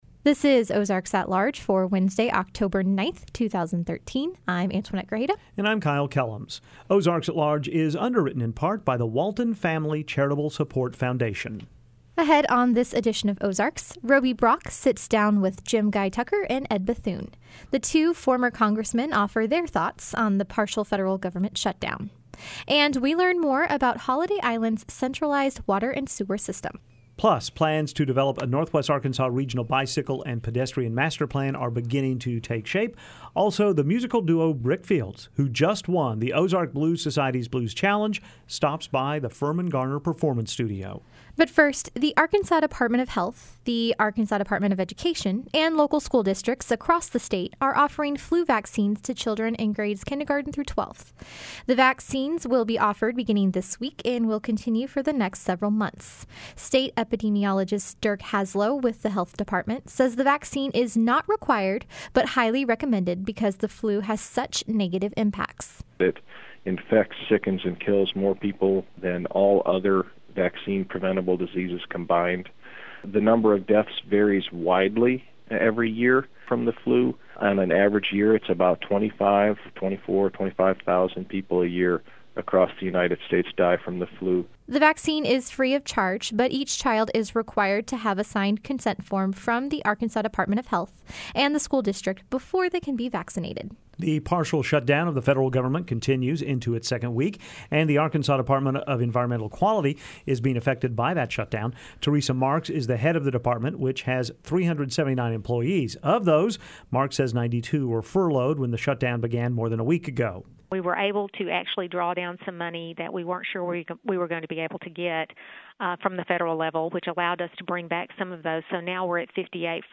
Ahead on Ozarks, a conversation with two former Congressmen about the partial federal government shutdown.